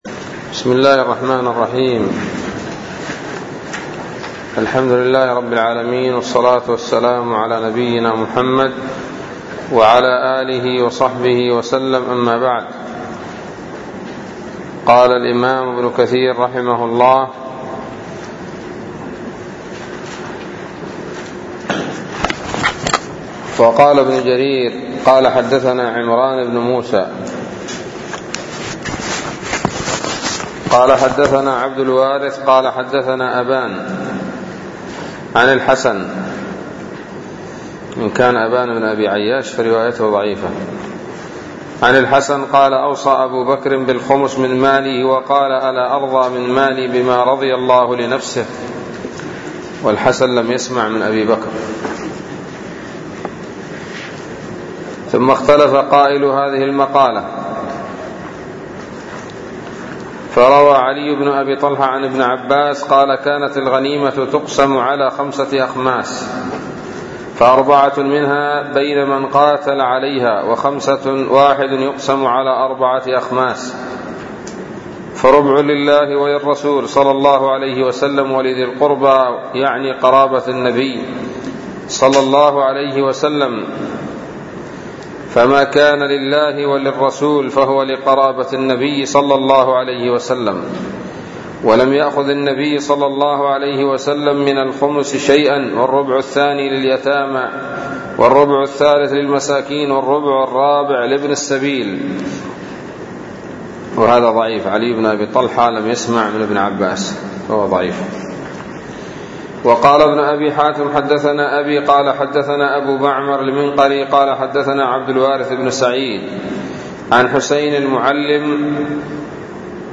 الدرس الرابع والعشرون من سورة الأنفال من تفسير ابن كثير رحمه الله تعالى